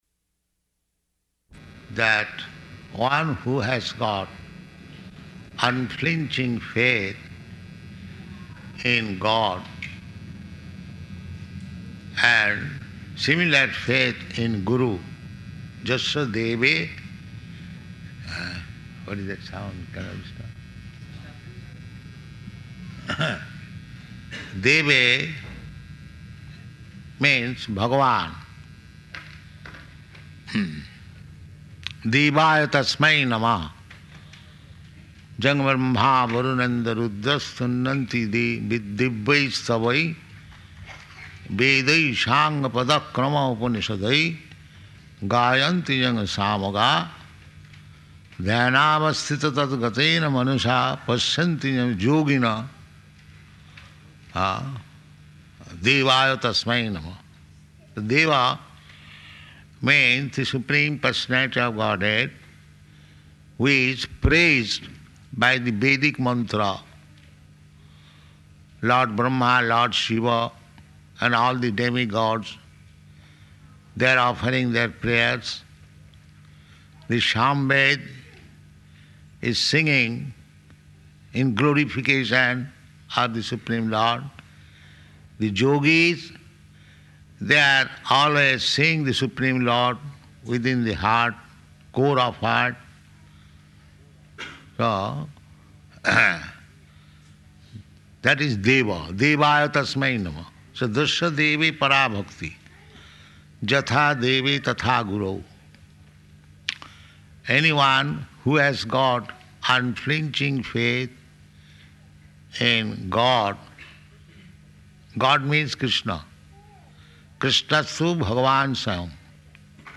Arrival Lecture
Type: Lectures and Addresses
Location: San Francisco
[buzzing feedback sound] [aside:] What is that sound?